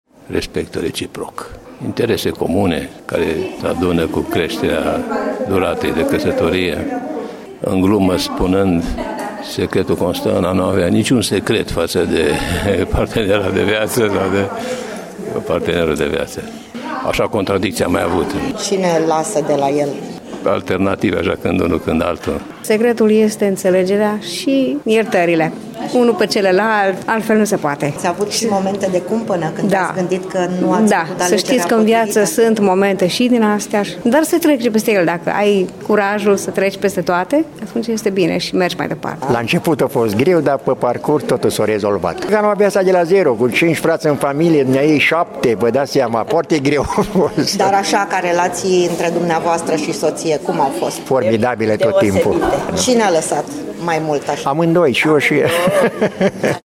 vox-50-ani-casatorie.mp3